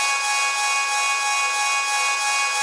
normal-sliderwhistle.wav